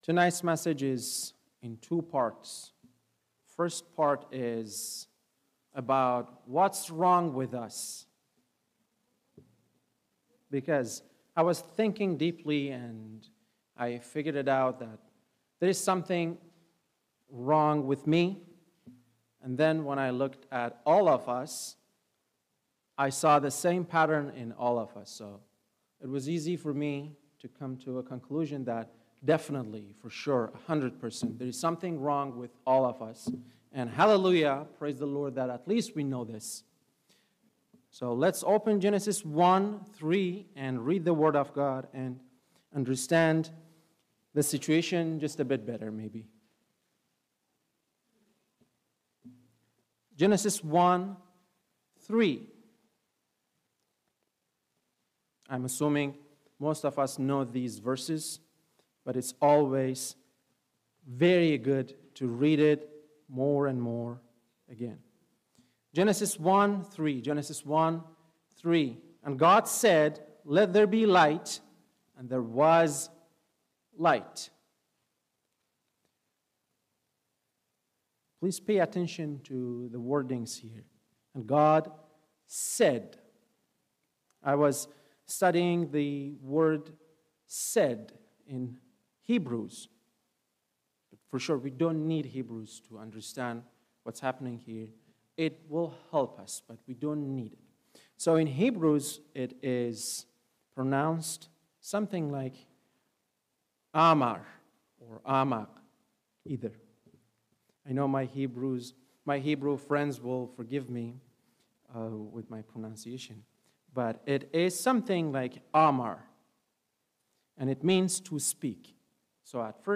Sermons | Anchor Baptist Church